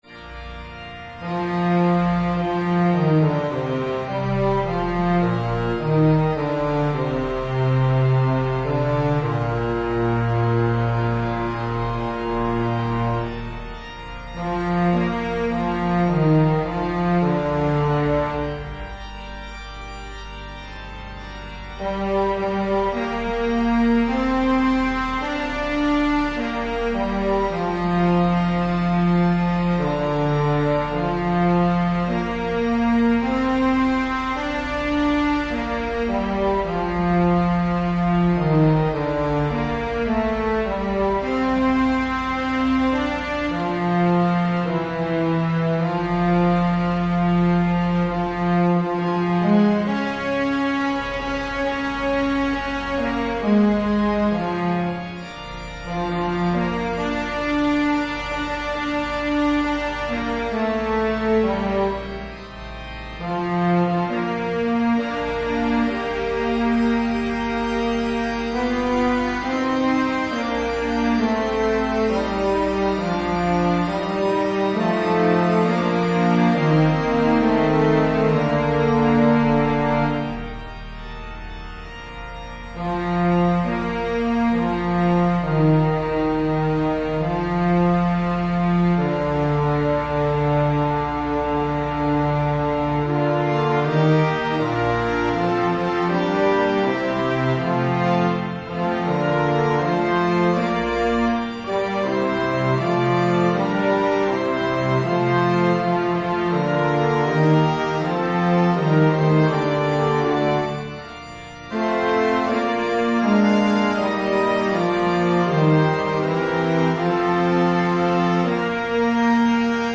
FullScore
Evensong Setting